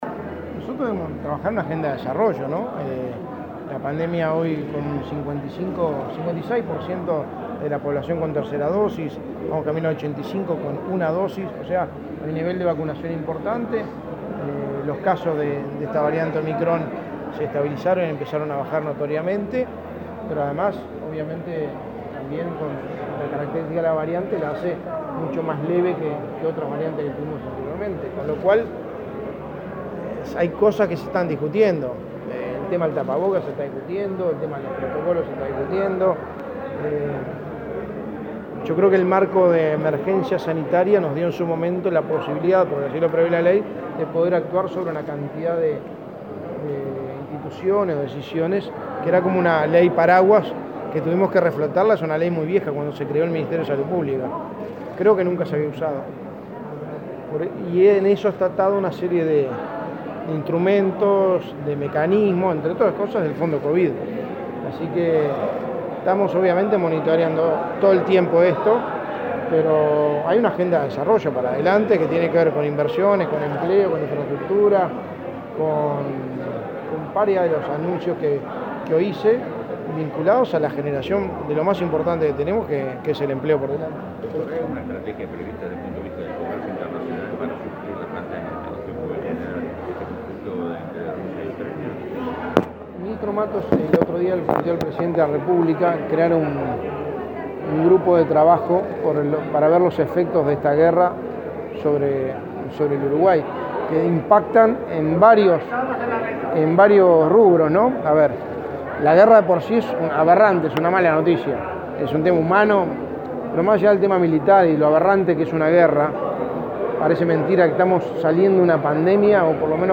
Declaraciones a la prensa del secretario de Presidencia, Álvaro Delgado